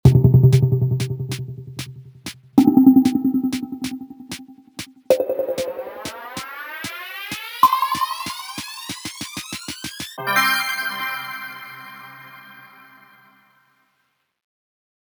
10sec_countdown.04f1284c.mp3